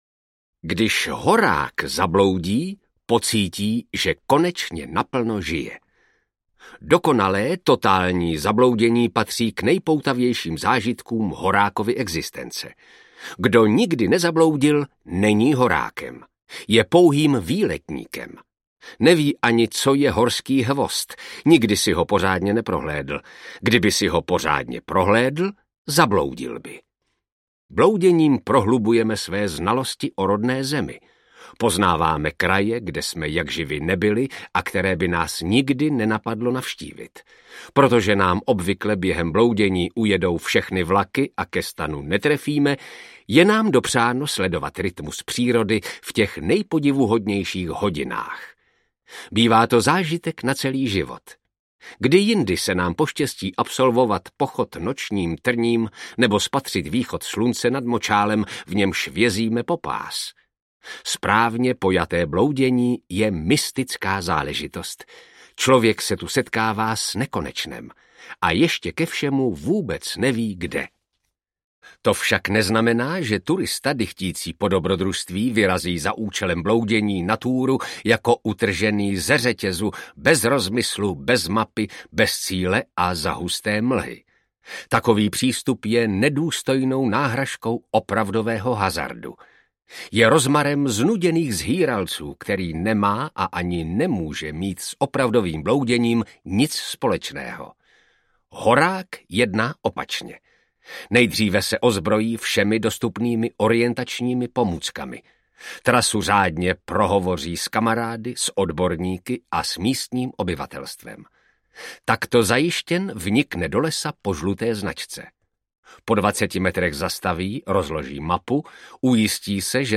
Proč bychom se nepotili aneb Jak se chodí po horách audiokniha
Ukázka z knihy
proc-bychom-se-nepotili-aneb-jak-se-chodi-po-horach-audiokniha